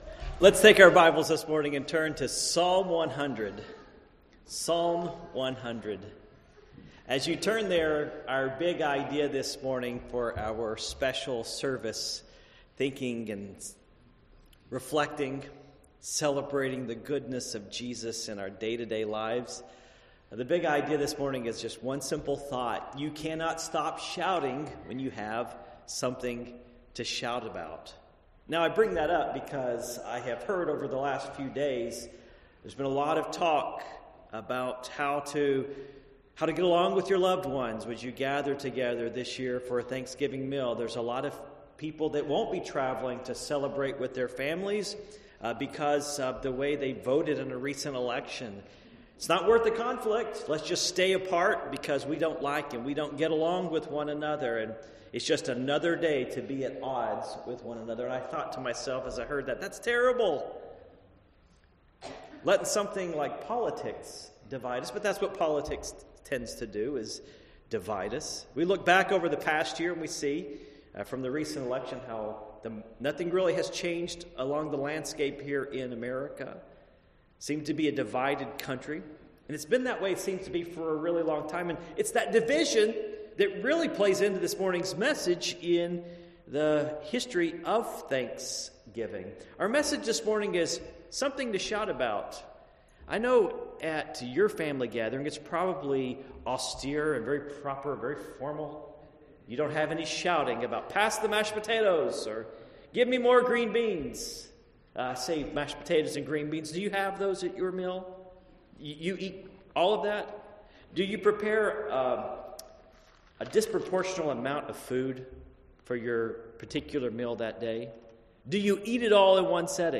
Psalm 100:1-5 Service Type: Morning Worship Psalms 100:1-5 1 A Psalm of praise.